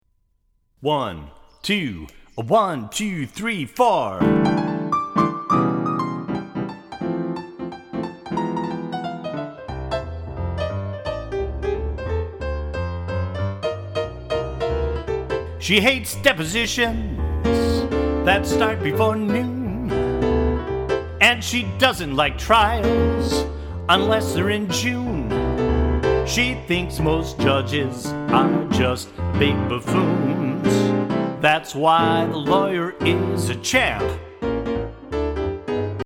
musical parodies